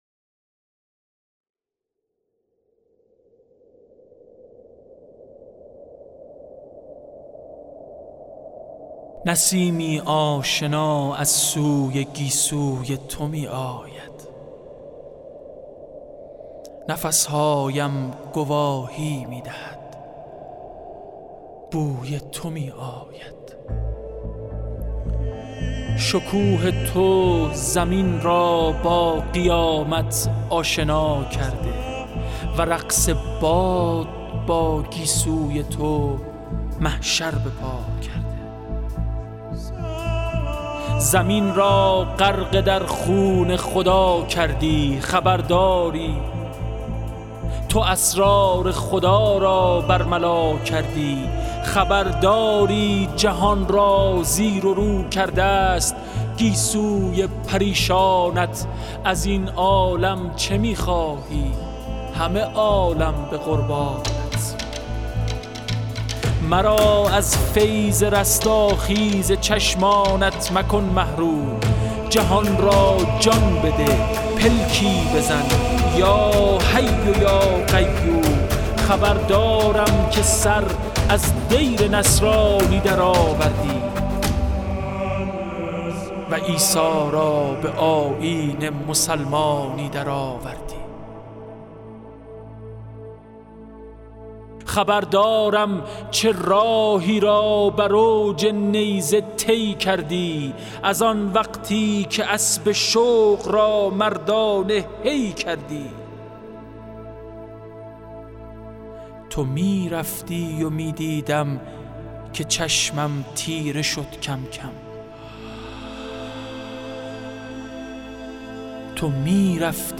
شعرخوانی حمیدرضا برقعی